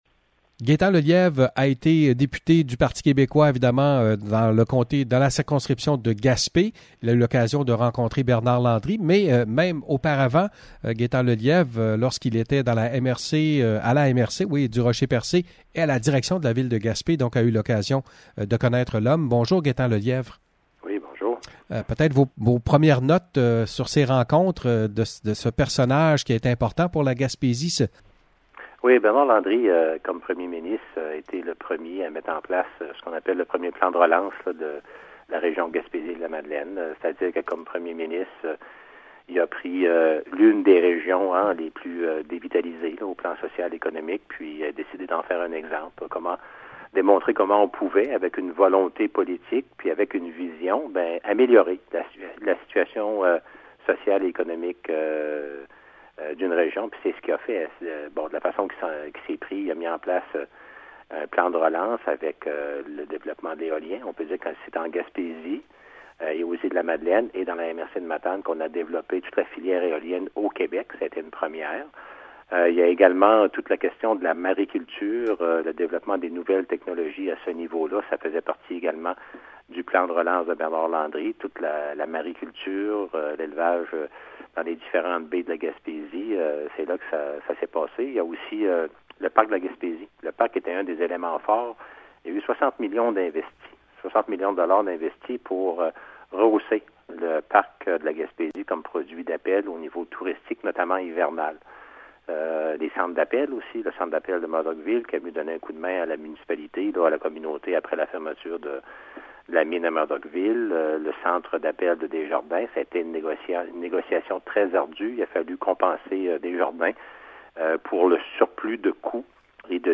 Entrevue avec Gaétan Lelièvre qui a rencontré Bernard Landry plusieurs reprise dans sa carrière: